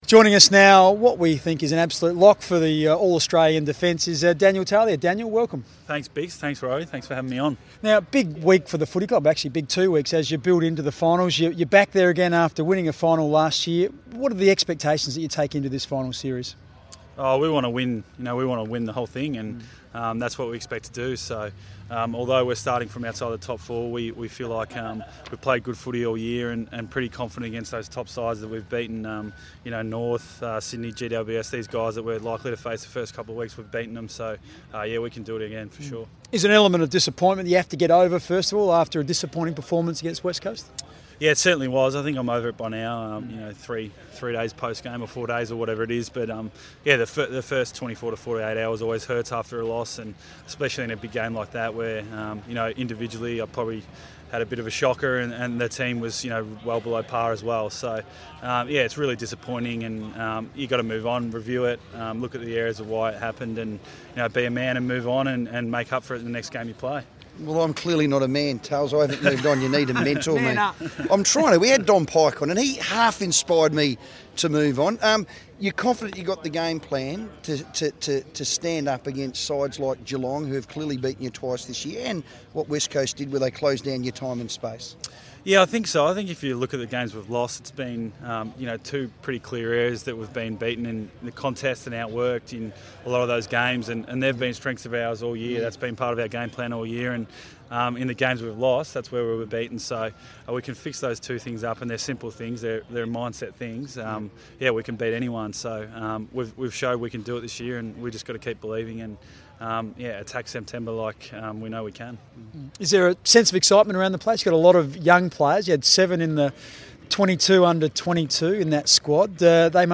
Daniel Talia talks on FIVEaa ahead of being named in the 2016 All Australian squad